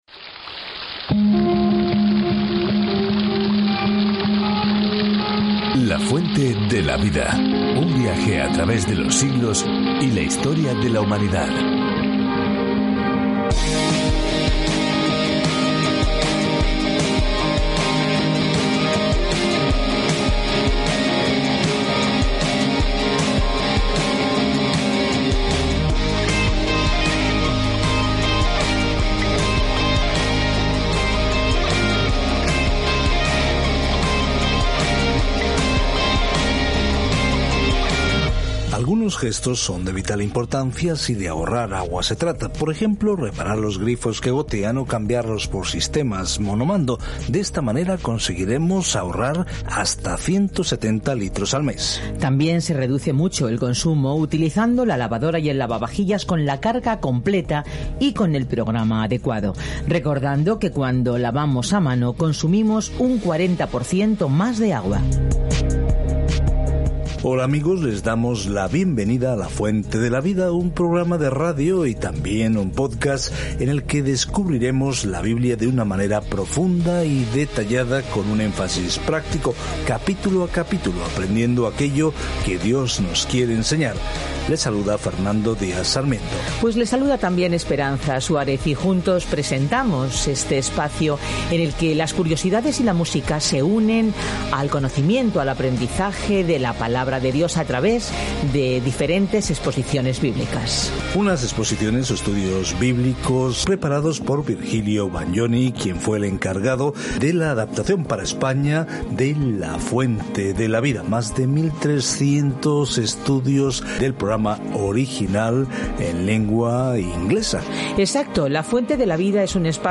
Escritura 2 CRÓNICAS 22:6-12 2 CRÓNICAS 23 2 CRÓNICAS 24 2 CRÓNICAS 25:1-14 Día 10 Iniciar plan Día 12 Acerca de este Plan En 2 Crónicas, obtenemos una perspectiva diferente de las historias que hemos escuchado sobre los reyes y profetas del pasado de Israel. Viaje diariamente a través de 2 Crónicas mientras escucha el estudio de audio y lee versículos seleccionados de la palabra de Dios.